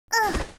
受伤音效.wav